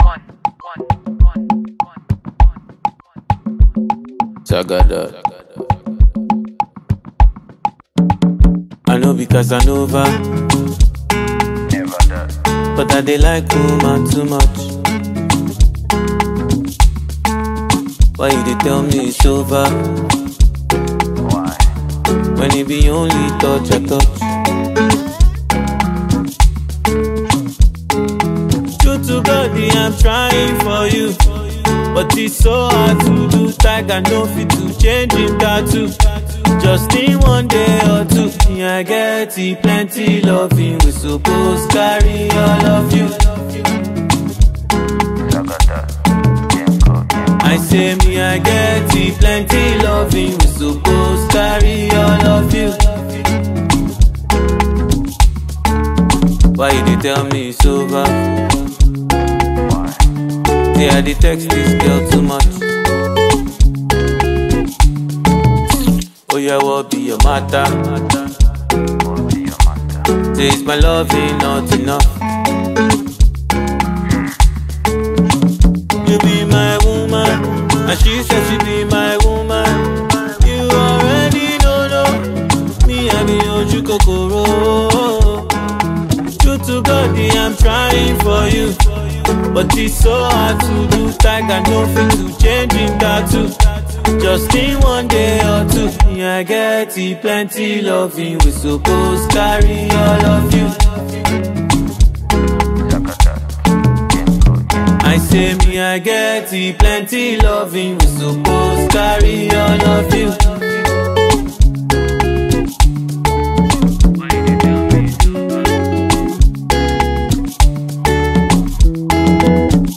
Talented singer and songwriter